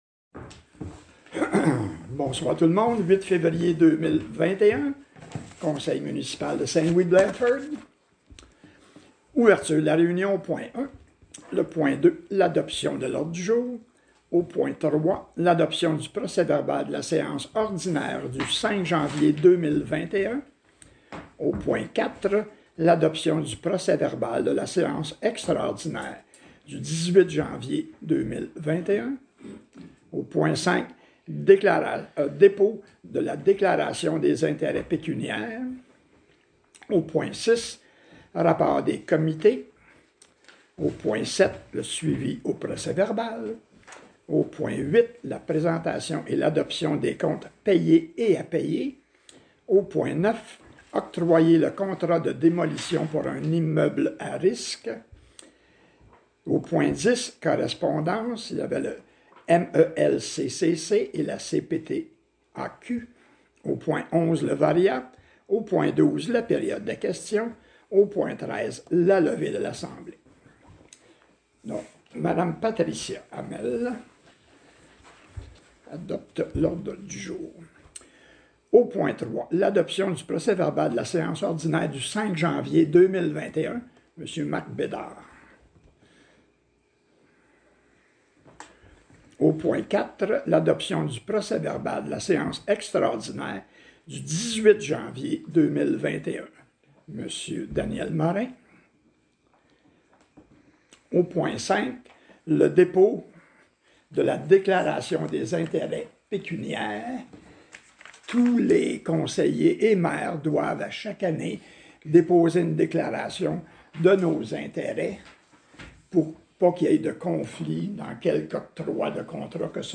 CONSEIL - séance ordinaire 8 Février 2021 – Municipalité de Saint-Louis-de-Blandford